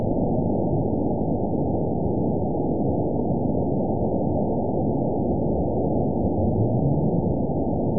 event 920824 date 04/10/24 time 21:54:53 GMT (1 year, 1 month ago) score 9.24 location TSS-AB02 detected by nrw target species NRW annotations +NRW Spectrogram: Frequency (kHz) vs. Time (s) audio not available .wav